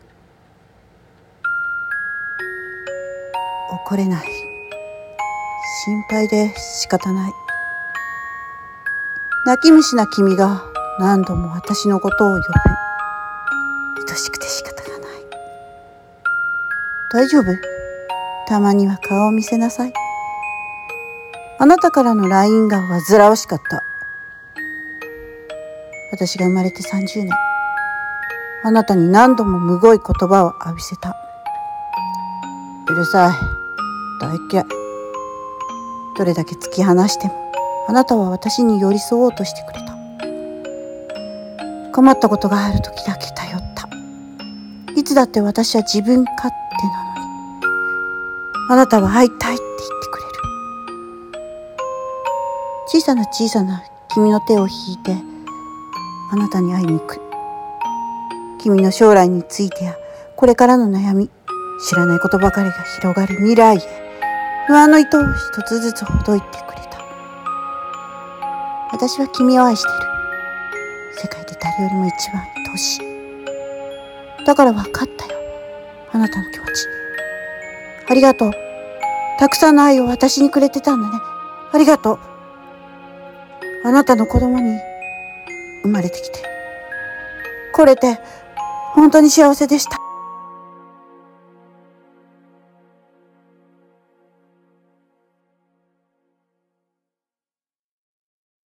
朗読台本【母へ】